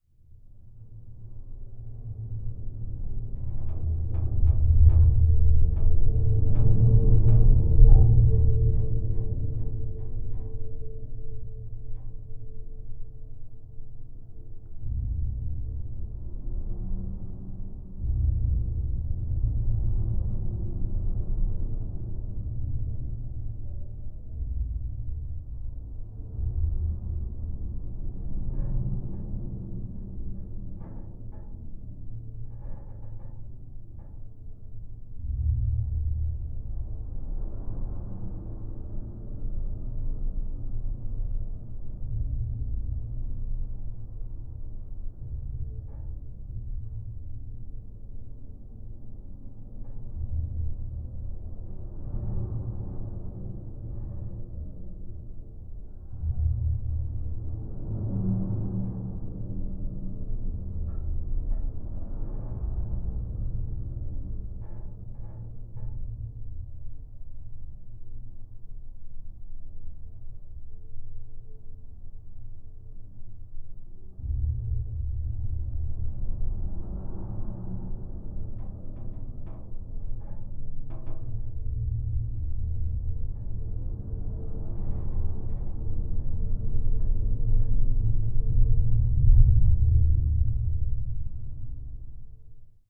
Originally designed for seismic measurements, it can be used with regular field recording equipment to capture very faint vibrations in various materials and even soil.
Bridge handrail (unprocessed)
snp-bridge-handrail.mp3